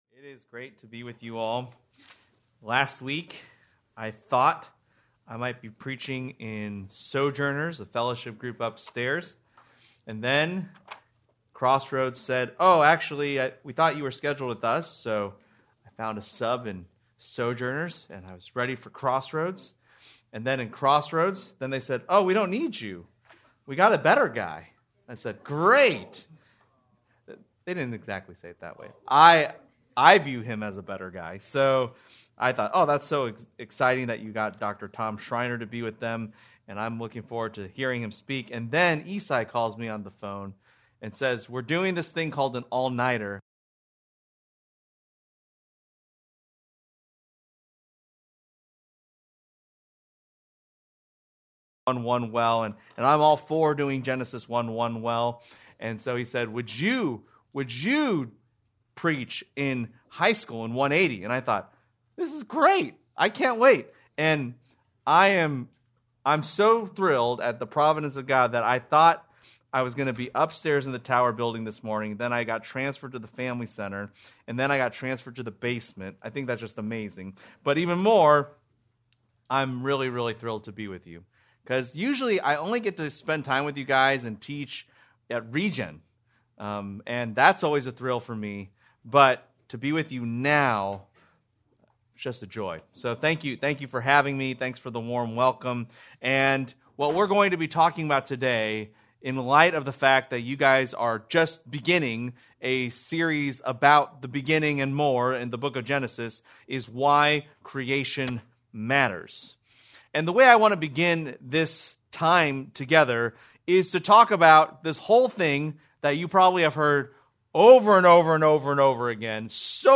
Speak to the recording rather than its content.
Please note, due to technical difficulties, this recording skips brief portions of audio.